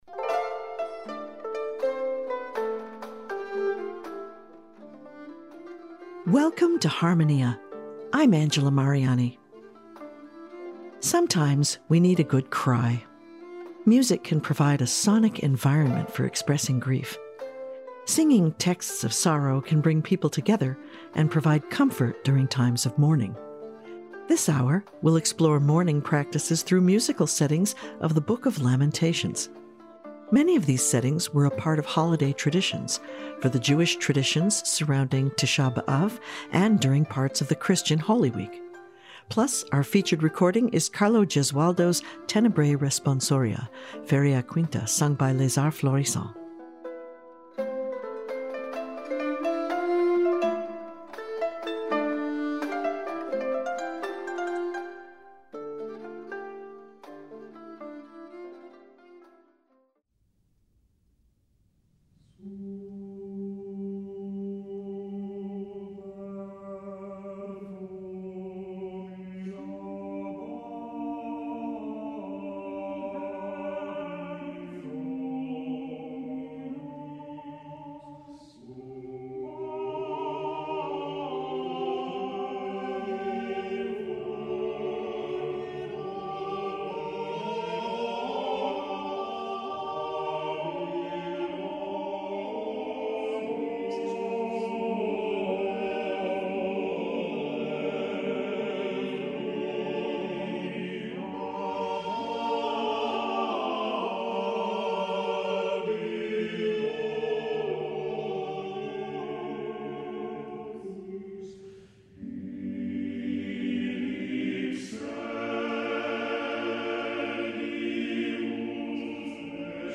This week on Harmonia, we’ll be exploring musical settings of the Book of Lamentations. We’ll hear uses of this text in traditions from Tisha b’Av to Tenebrae.